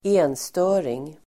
Ladda ner uttalet
enstöring substantiv, hermit , recluse Uttal: [²'e:nstö:ring]